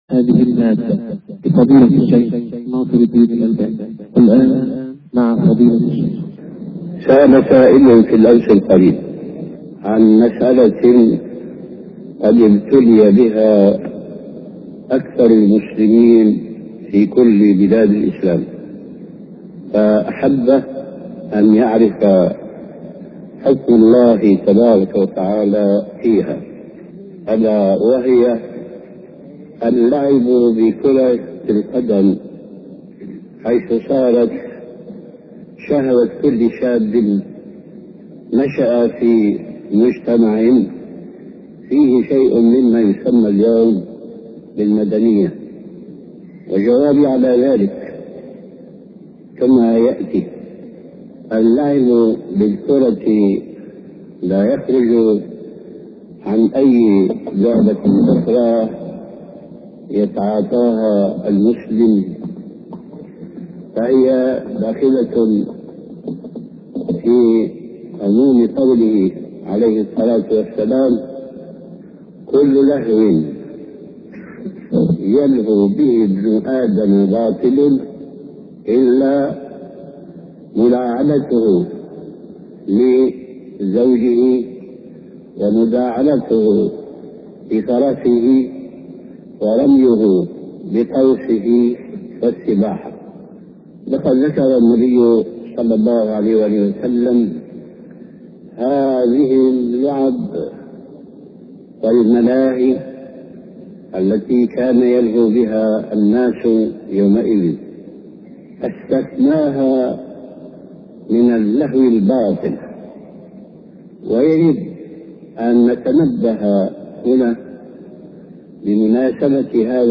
شبكة المعرفة الإسلامية | الدروس | اللهو المباح واللهو الباطل |محمد ناصر الدين الالباني